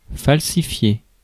Ääntäminen
IPA : /ˈfɒlsɪfaɪ/